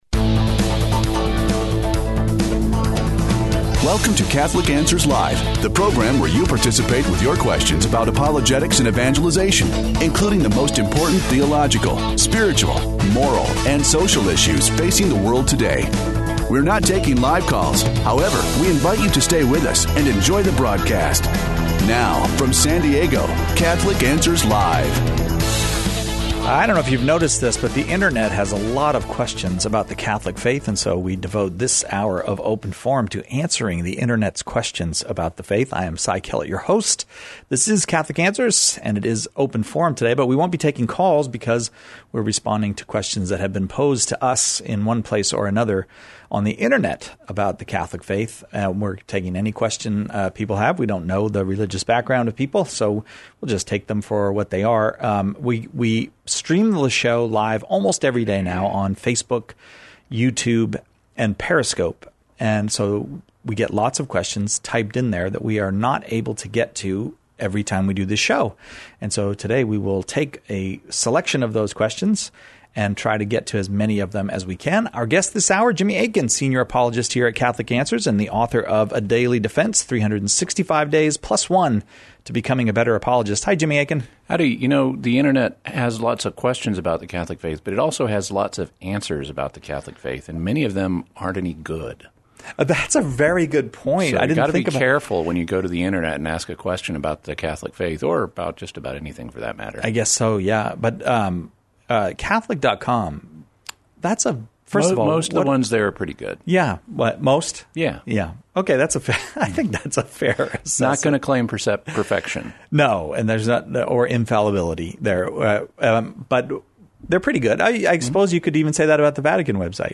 Open Forum (Pre-recorded)